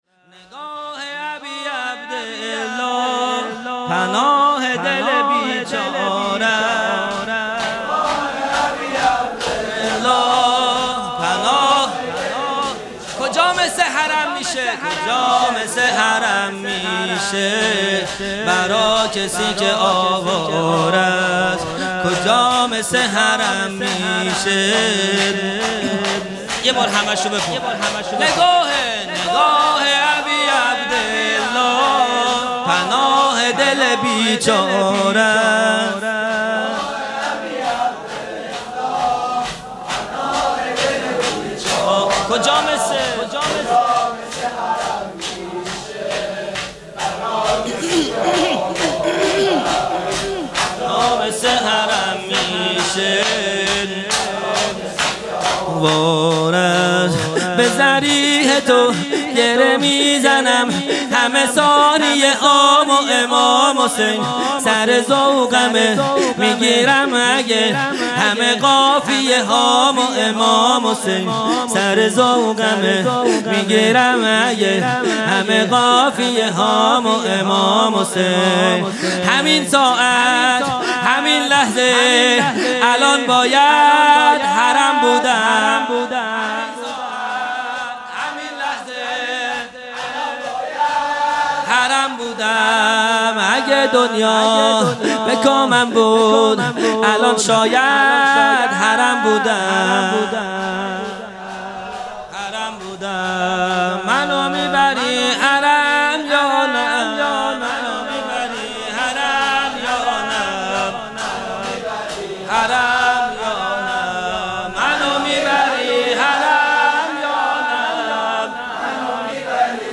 شور | نگاه ابی عبدالله پناه دل بیچارست | ۲۹ اردیبهشت 1401
جلسۀ هفتگی | به مناسبت شهادت حضرت حمزه(ع) | پنجشنبه 2۹ اردیبهشت 1401